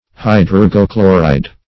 Search Result for " hydrargochloride" : The Collaborative International Dictionary of English v.0.48: Hydrargochloride \Hy*drar"go*chlo"ride\, n. [Hydrargyrum + chloride.]
hydrargochloride.mp3